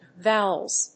/ˈvaʊʌlz(米国英語)/